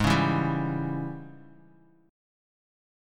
G#M7sus4#5 chord